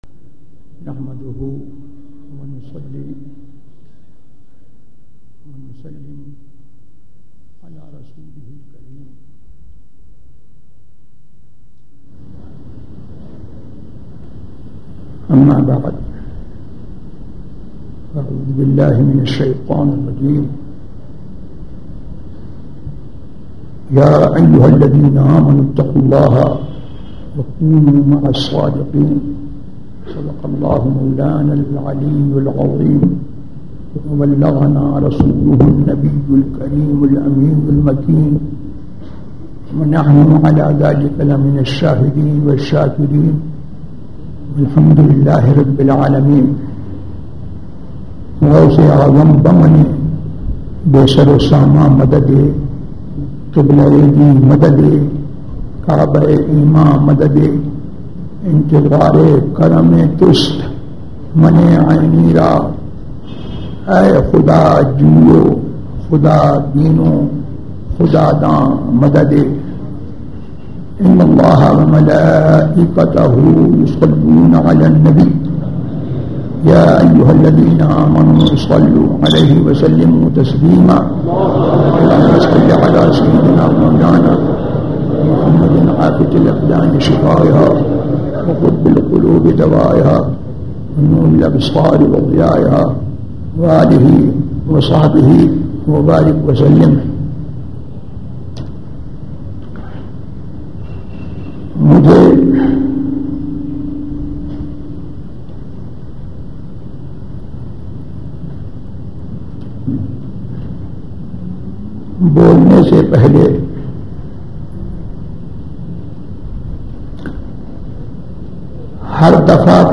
Khitab Jumah at Darussalam
خطاب جمعہ دارالسلام میں
تقاریر
khitab-jumah-at-darussalam.mp3